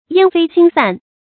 煙飛星散 注音： ㄧㄢ ㄈㄟ ㄒㄧㄥ ㄙㄢˋ 讀音讀法： 意思解釋： 形容離散。